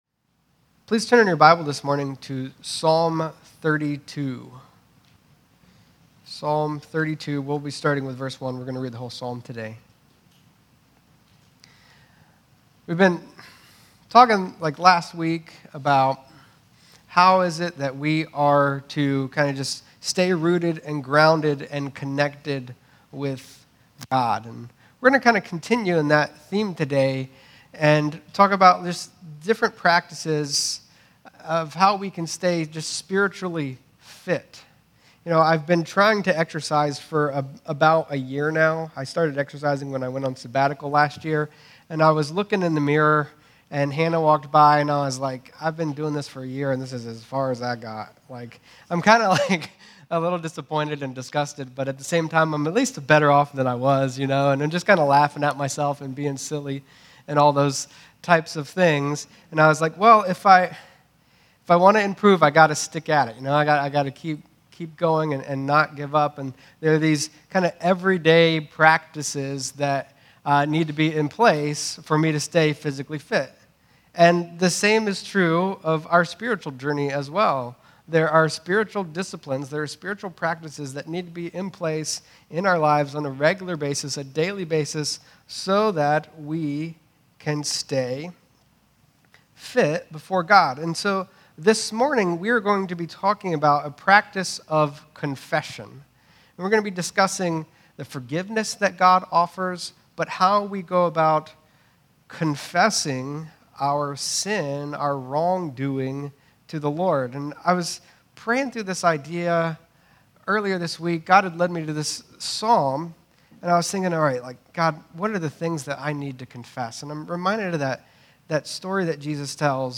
Sermons | New Life Church